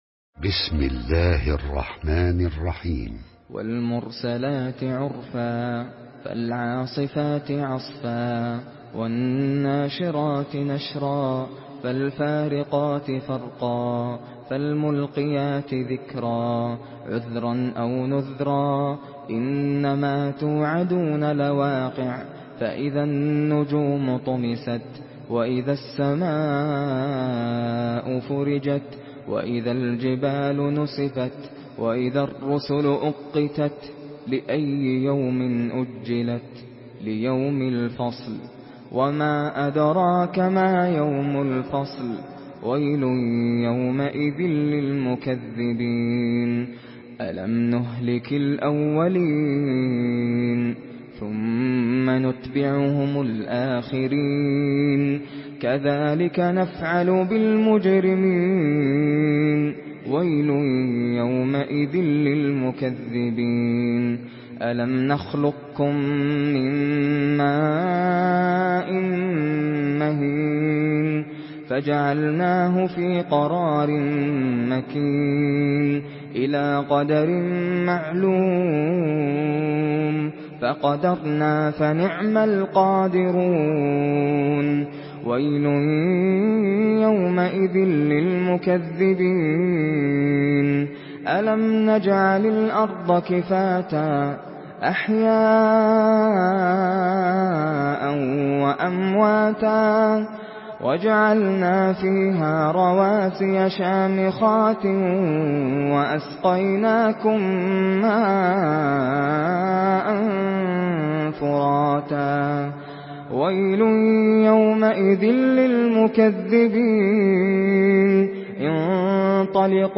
Surah Mürselat MP3 in the Voice of Nasser Al Qatami in Hafs Narration
Murattal Hafs An Asim